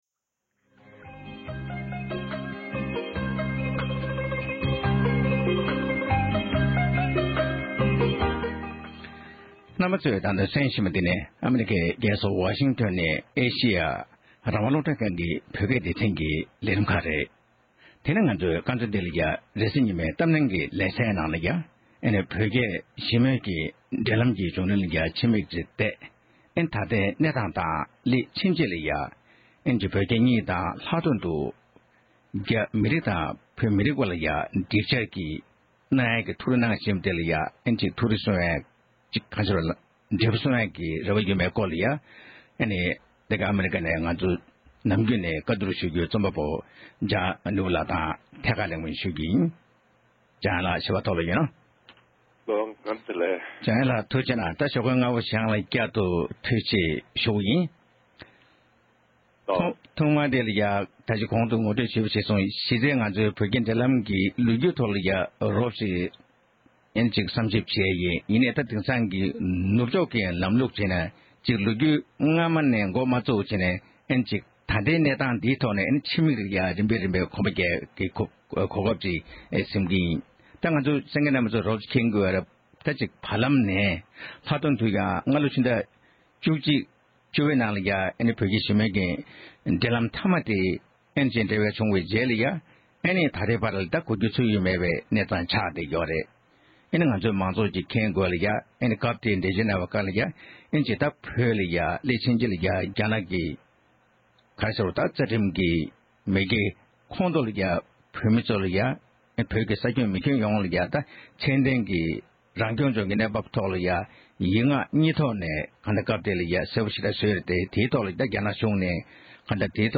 བོད་རྒྱ་ཞི་མོལ་གྱི་བྱུང་རིམ་དང་ད་ལྟའི་གནས་སྟངས་དེ་བཞིན་འབྱུང་འགྱུར་མི་རིགས་གཉིས་བར་ཞི་འགྲིགས་ཀྱི་ཐུགས་རེ་ཡོད་མིན་ཐད་གླེང་མོལ།